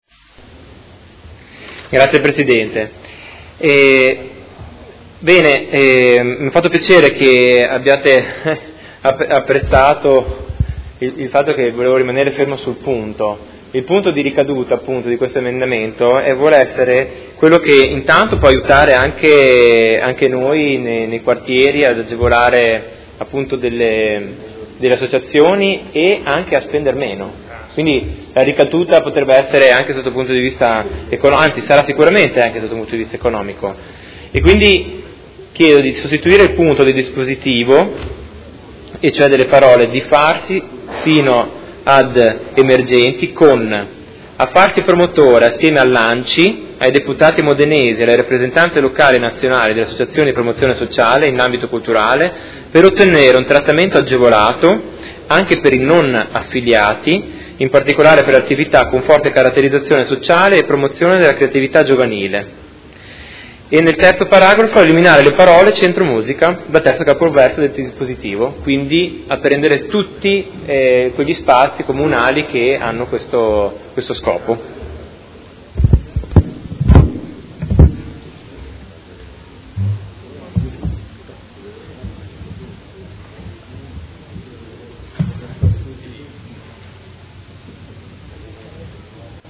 Seduta del 28 gennaio. Ordine del Giorno presentato dal Gruppo Consiliare Per Me Modena avente per oggetto: Agevolazioni di spettacoli dal vivo e snellimento amministrativo.